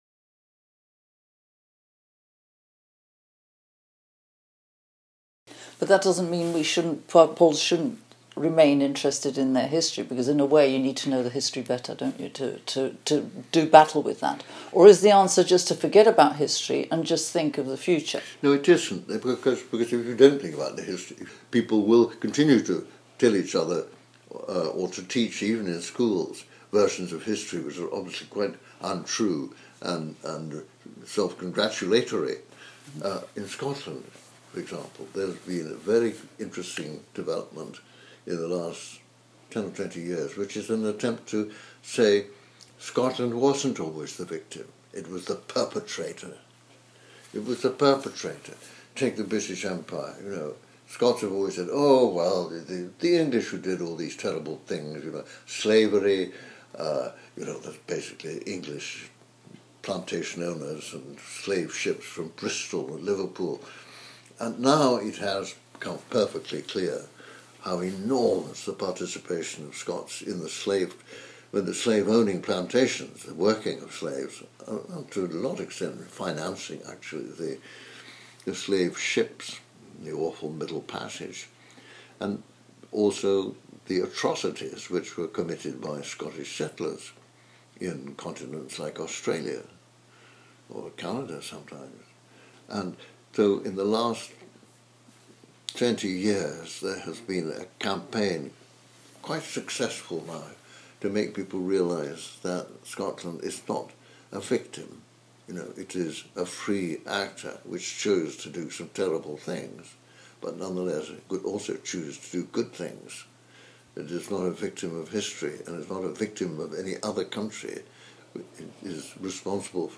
This part of the interview was not included in Episode 58: